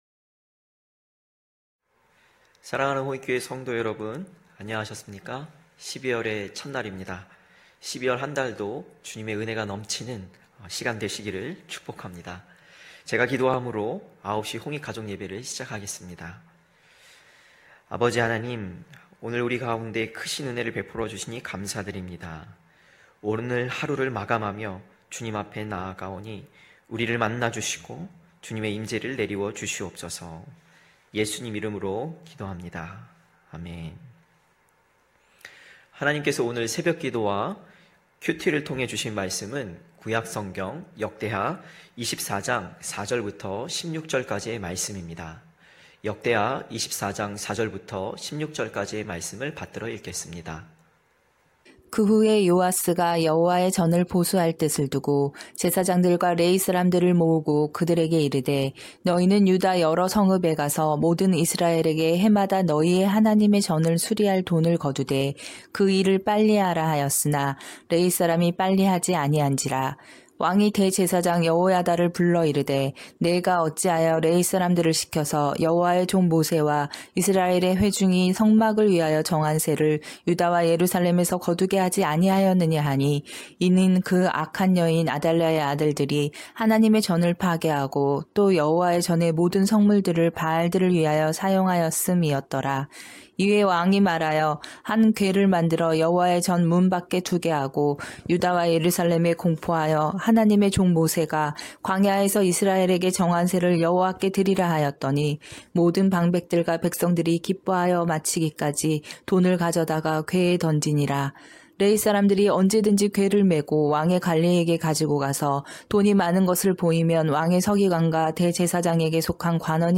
9시홍익가족예배(12월1일).mp3